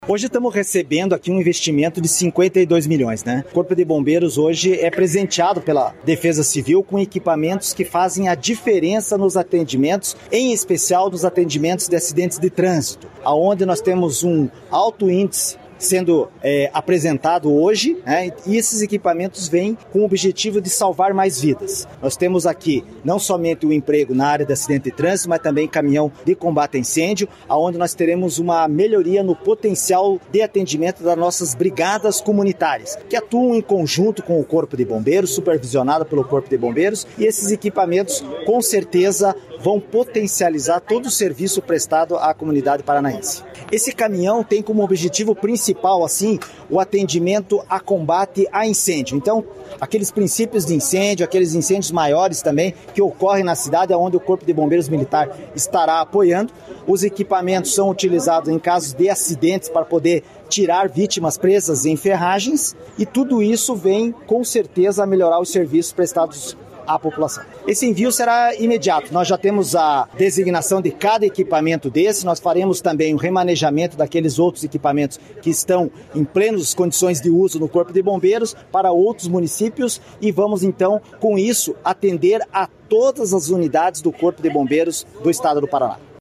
Sonora do comandante-geral do Corpo de Bombeiros Militar do Paraná, coronel Manoel Vasco de Figueiredo Junior, sobre a entrega de R$ 52 milhões em veículos e equipamentos para a Defesa Civil